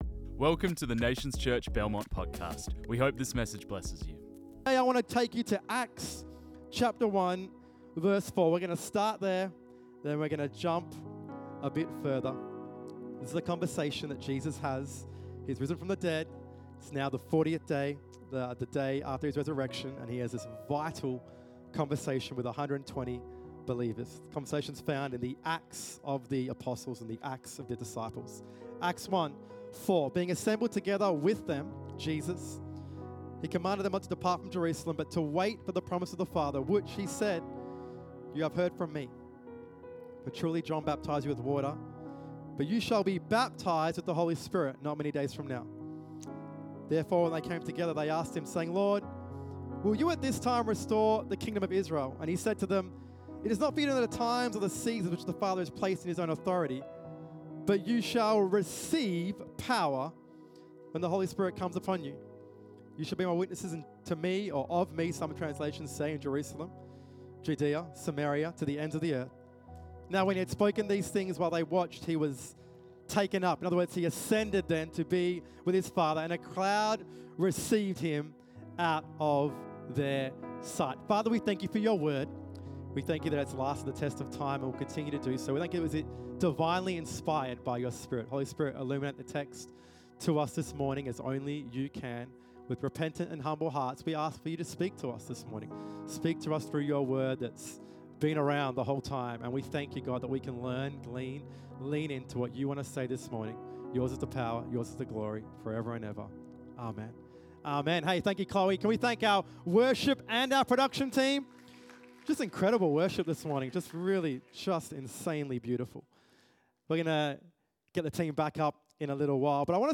This message was preached on 08 June 2025.